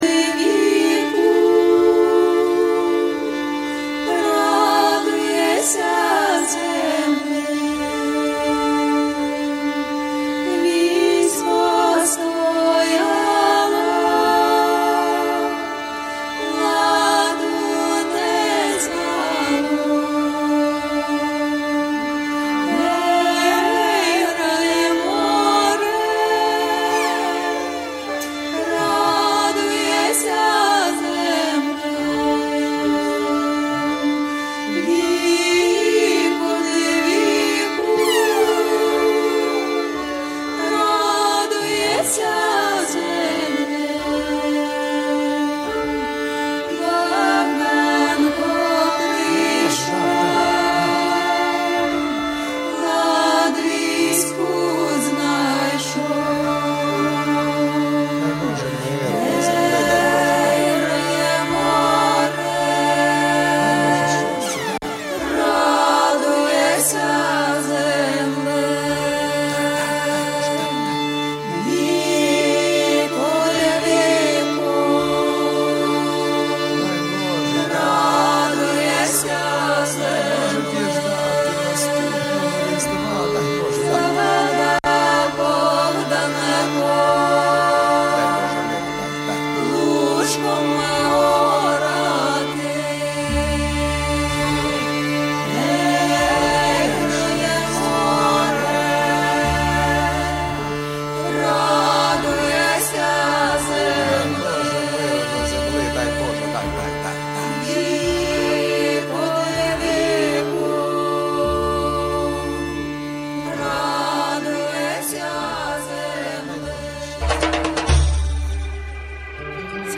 Radio Schalom berichtet �ber j�disches Leben in Deutschland, Diskussionen �ber Tradition und Religion, Juden in Israel und in der Diaspora sowie Musik aus Israel und anderen Teilen der Welt.